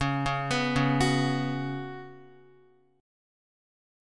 C#7sus4 chord